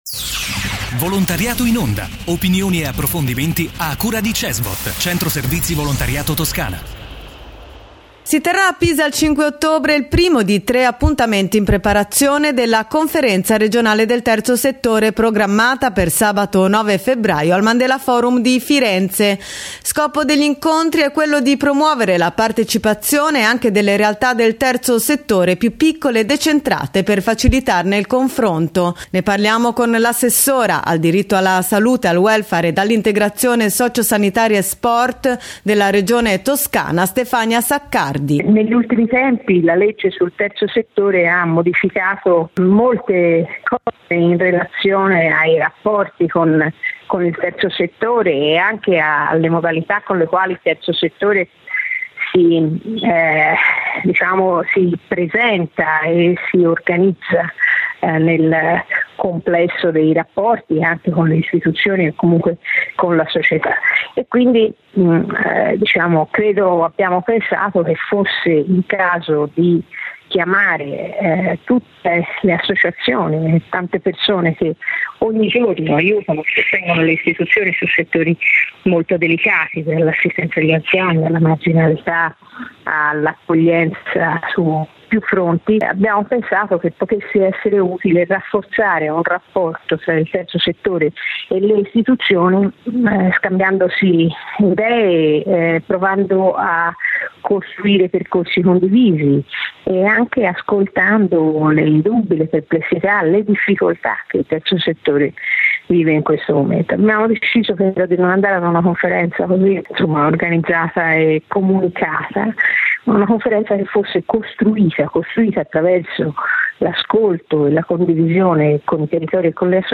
Intervista a Stefania Saccardi, assessore assessore al diritto alla salute, al welfare, all’integrazione socio-sanitaria e sport Regione Toscana